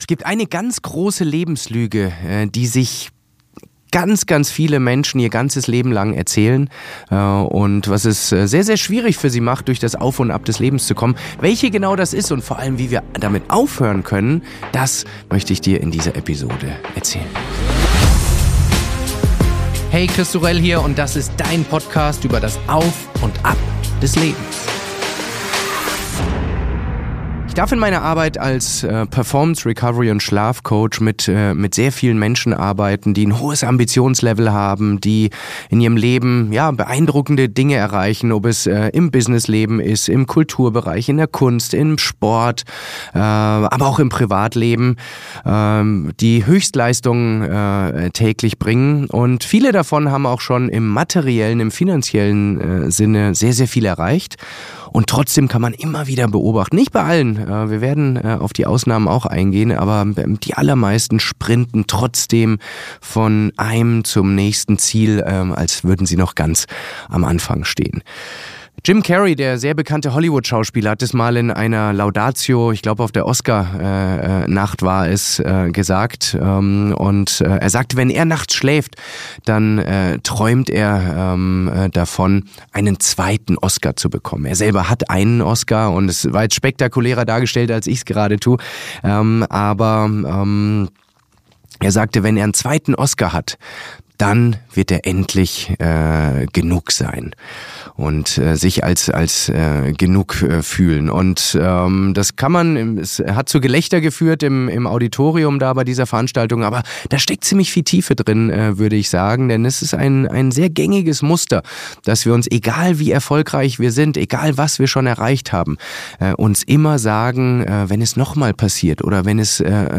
Es gibt eine große weitverbreitete Lebenslüge, die es Menschen unnötig schwer macht, das Auf und Ab ihres Leben zu mangen. Welche genau das ist und vor allem, wie wir damit aufhören können, darum geht es in dieser kurzen Solo-Episode.